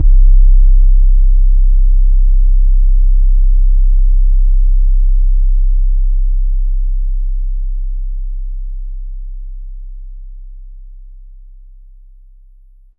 AllStar808_YC.wav